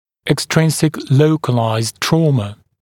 [ek’strɪnsɪk ‘ləukəlaɪzd ‘trɔːmə][эк’стринсик ‘лоукэлайзд ‘тро:мэ]внешняя локальная травма